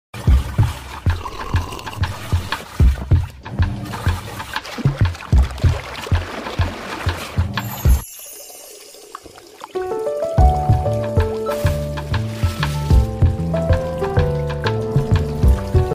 Robot Washing Machine 🤖🌀 sound effects free download